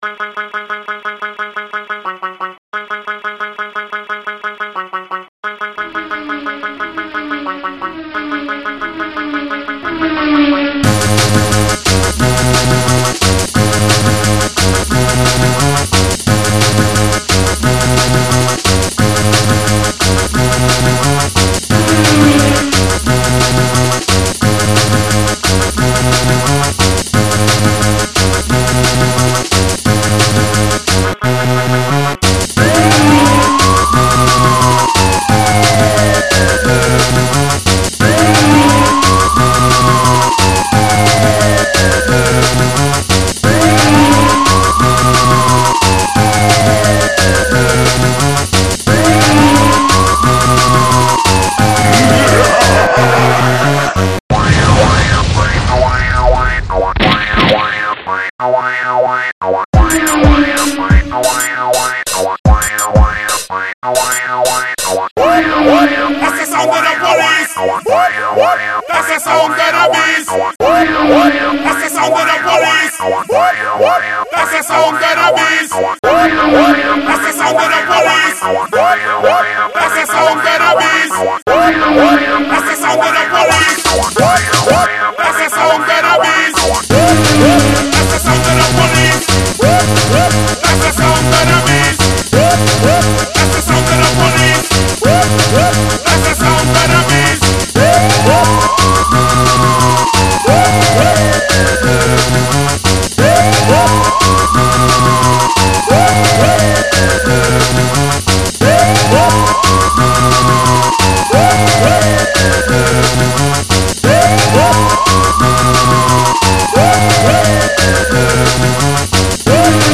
Шустрый drum & bass d'n'b